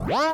alarm_siren_loop_07.wav